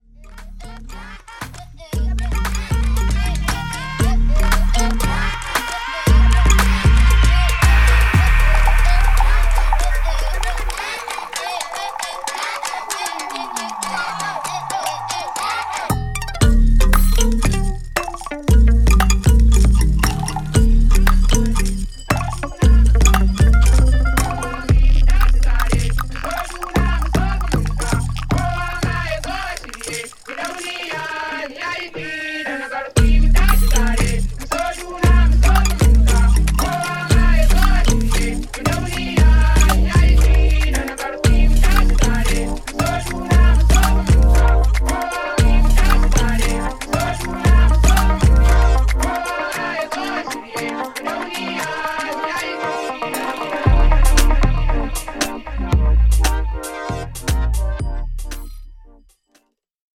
ホーム ｜ WORLD MUSIC > WORLD MUSIC